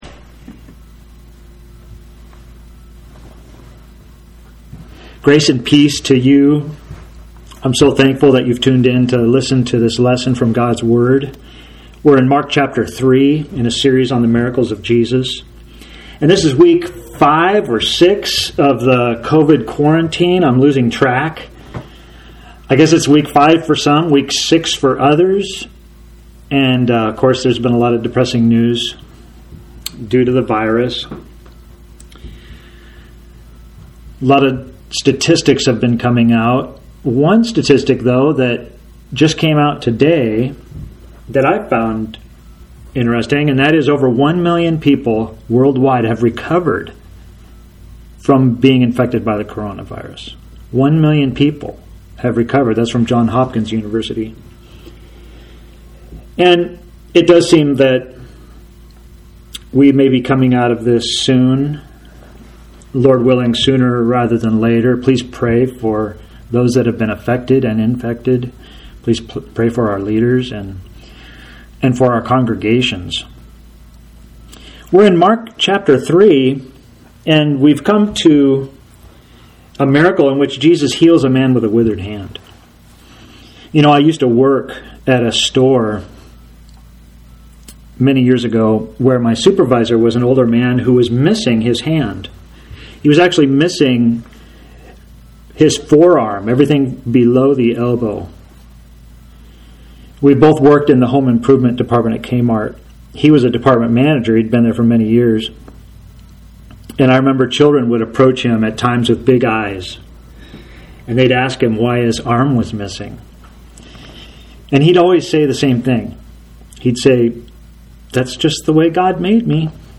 Sermon for May 3, 2020 – Lesson 9 in a series on the Miracles of Jesus, where the power of Christ is creating conflict among the Pharisees and scribes of the Law.